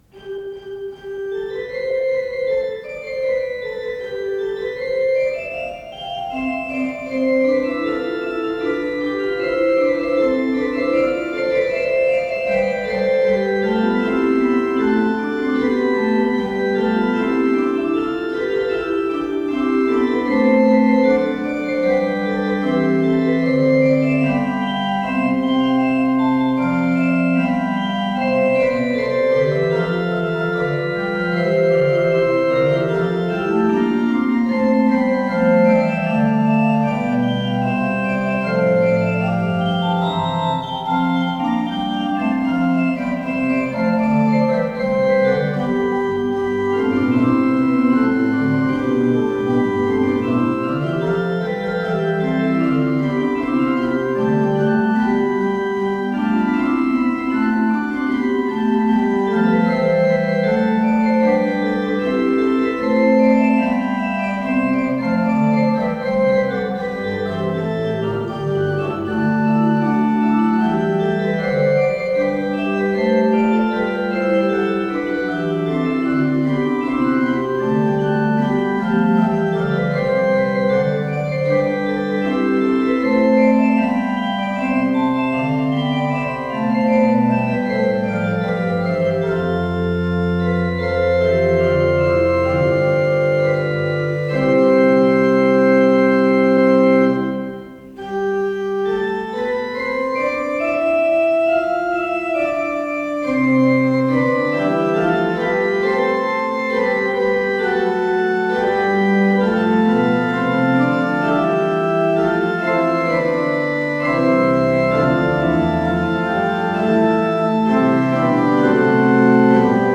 с профессиональной магнитной ленты
ПодзаголовокДо мажор
ИсполнителиГарри Гродберг - орган
ВариантДубль моно